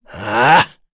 Kain-Vox_Attack4_kr.wav